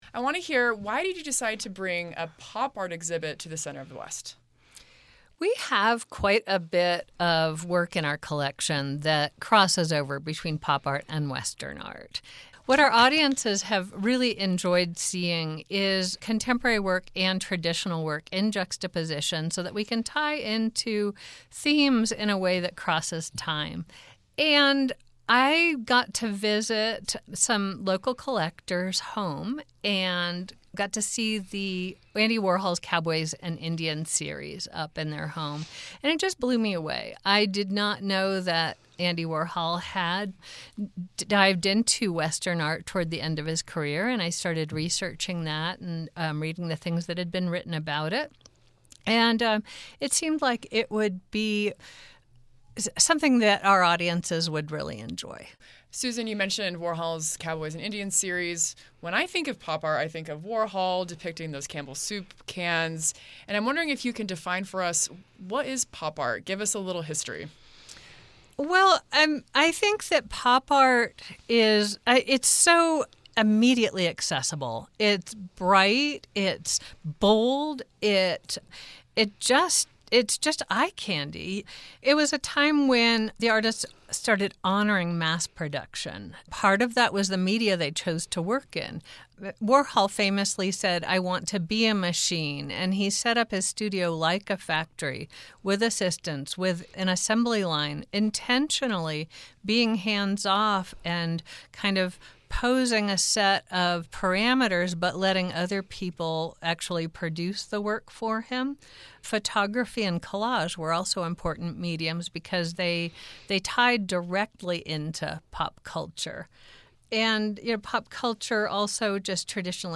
The exhibition invites viewers to reflect on the evolving meaning of the West in contemporary culture. Click below to listen to the full interview. https